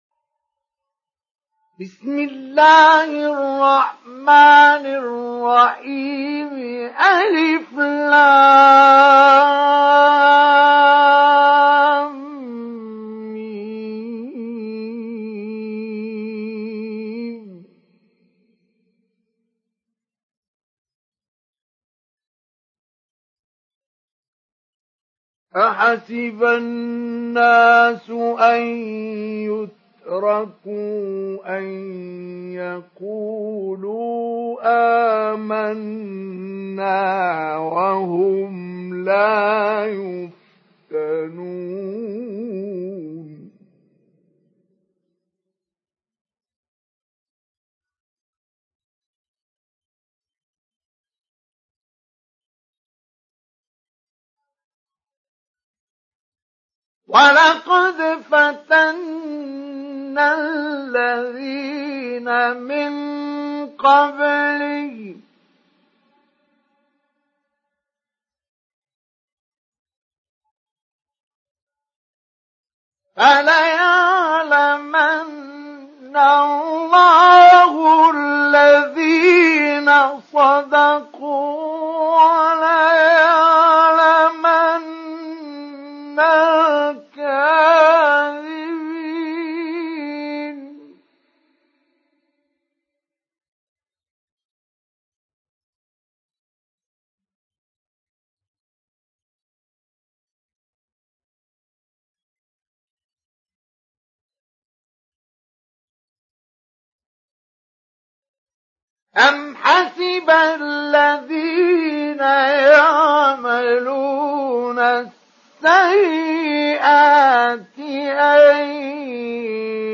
سُورَةُ العَنكَبُوتِ بصوت الشيخ مصطفى اسماعيل